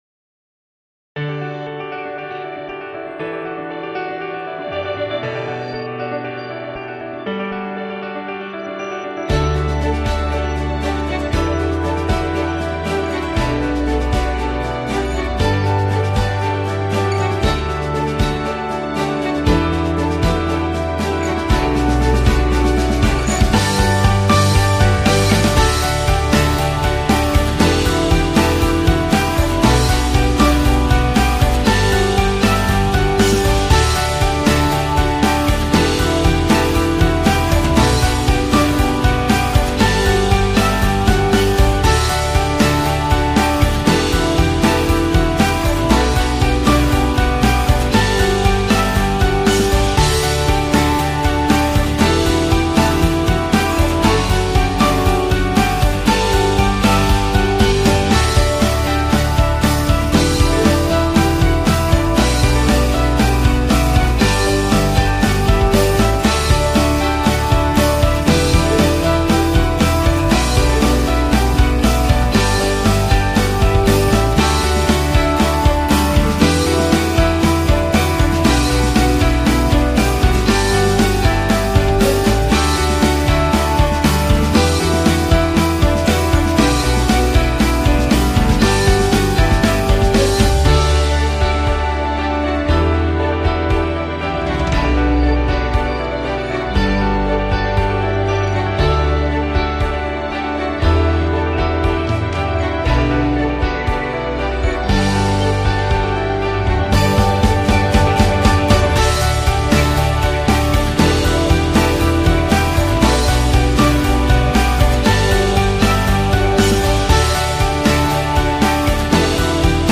دانلود اهنگ غمگین بی کلام برای ساخت کلیپ غمگین